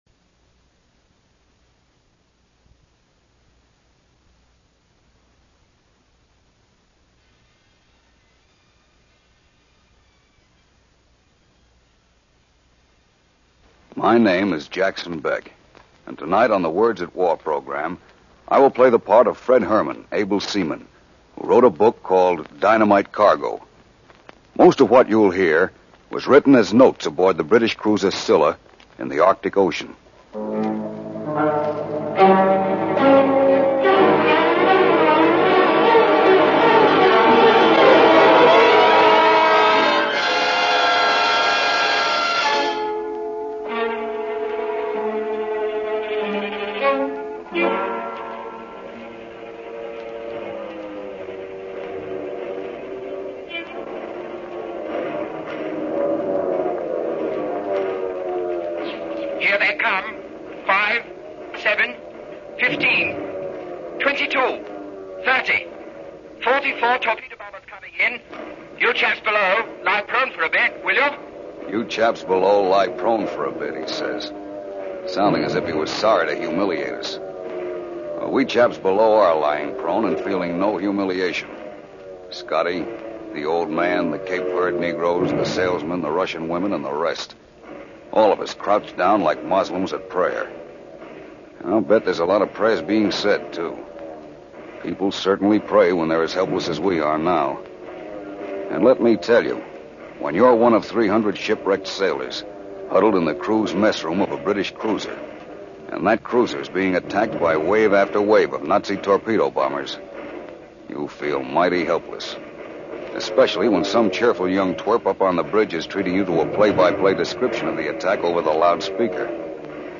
Jackson Beck plays the part of U.S. able seaman Fred Herman who wrote a book called Dynamite Cargo.